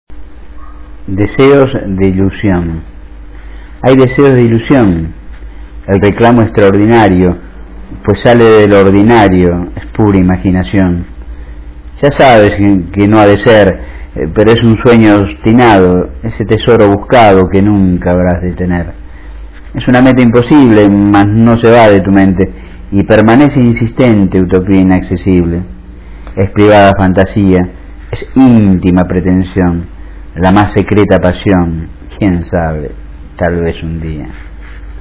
Recitado por el autor (0:34", 137 KB)